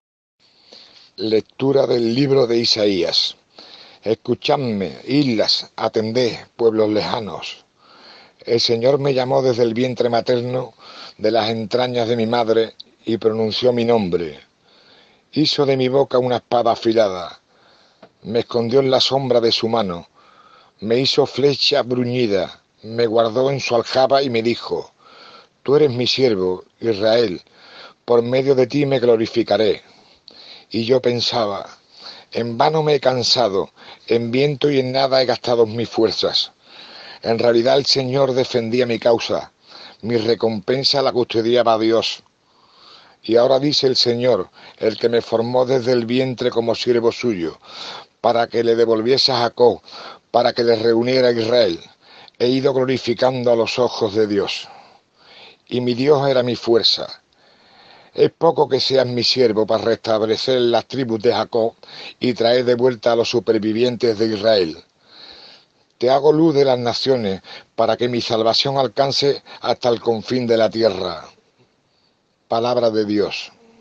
Lectura
Lectura-Martes-santo.m4a